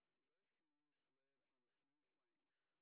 sp01_train_snr20.wav